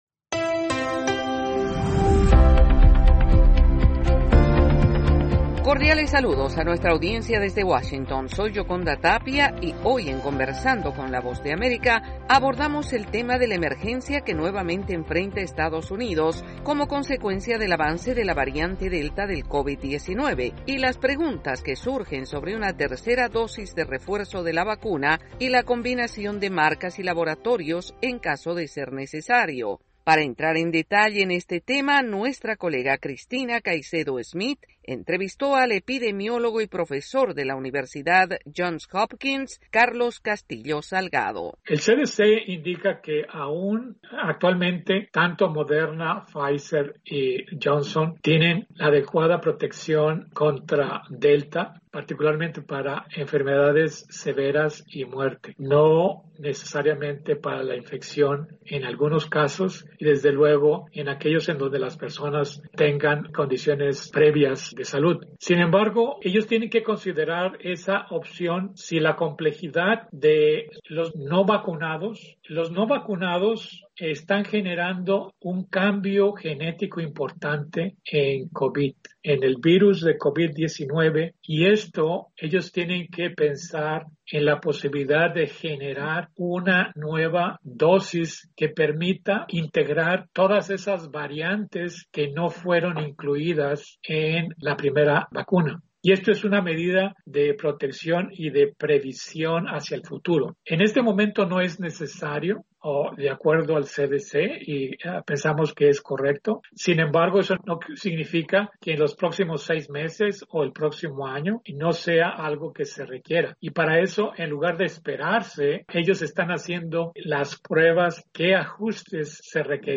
Diálogo con el epidemiólogo y profesor de la Universidad Johns Hopkins